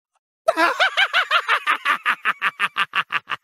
Gold Watchers Laughing
goldwatcherlaugh.mp3